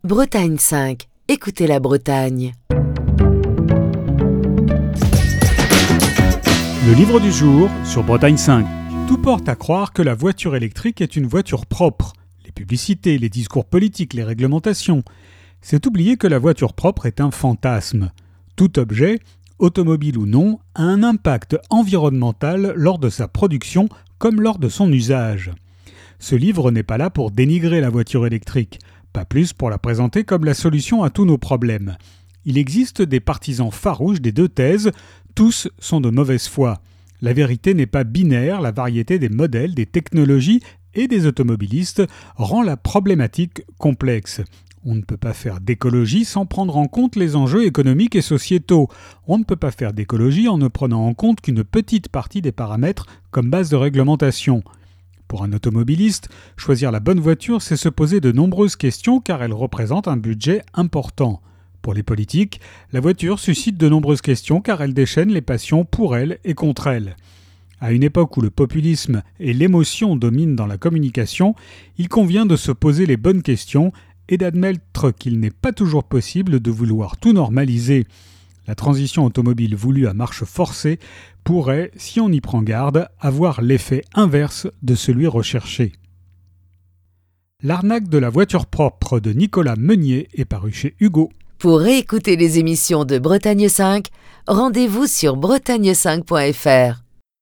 Chronique du 8 septembre 2021.